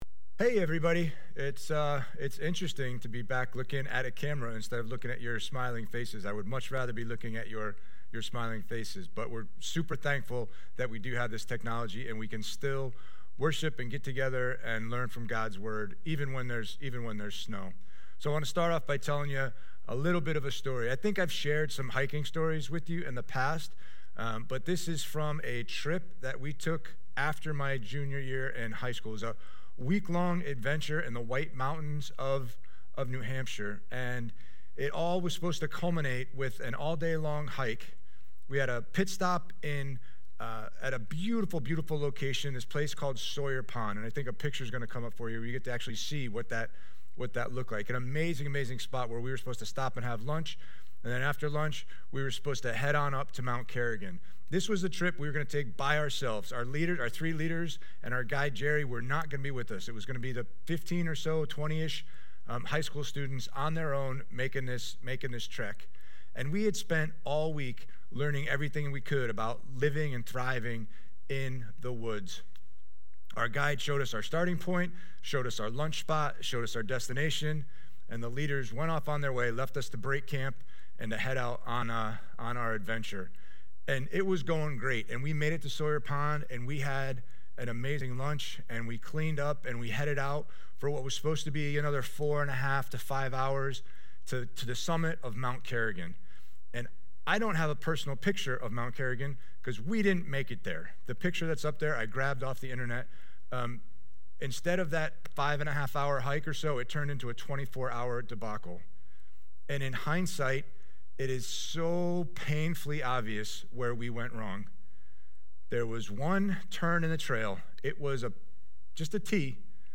Listen in as we continue our series on Proverbs, The Way of Wisdom. Today’s topic is Life or Death Timestamps: Welcome & Spiritual Practice: 0:43 Worship: 2:38 Sermon: 17:02 Worship: 42:47 Offering: 49:55 Song List: I Thank God Holy Forever Come Thou Fount Way of Wisdom